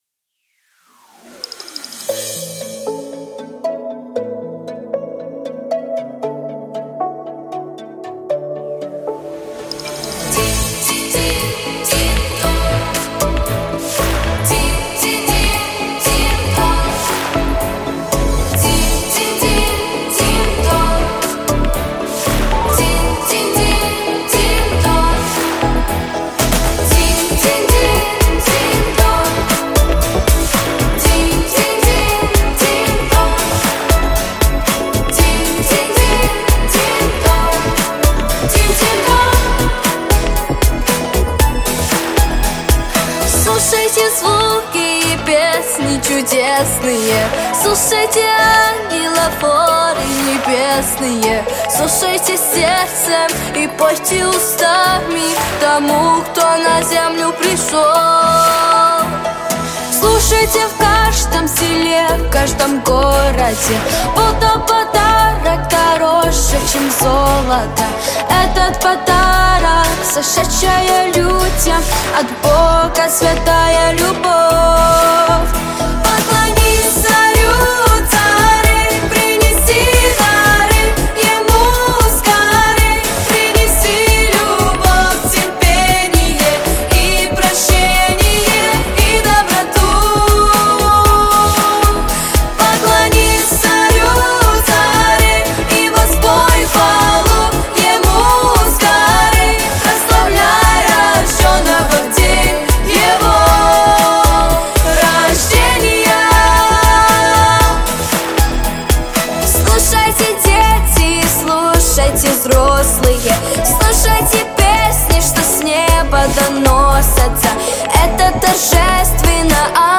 Христианские Песни ⛪